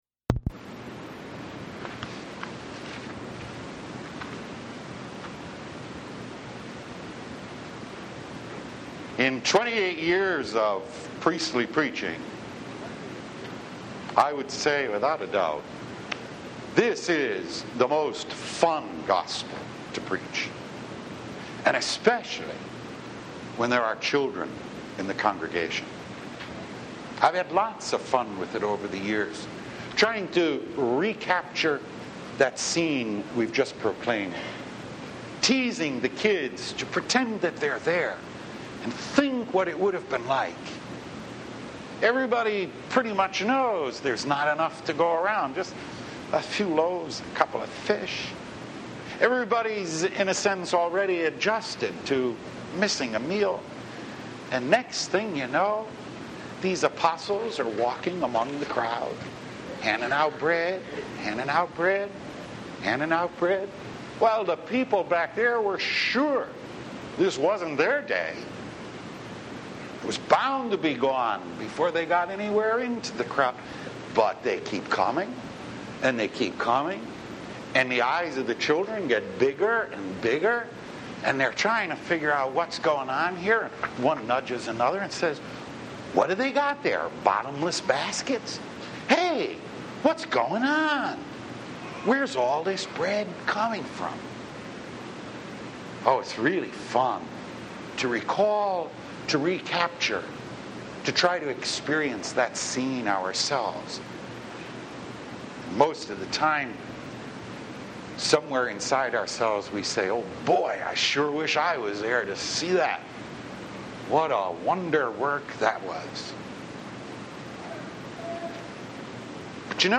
Calling « Weekly Homilies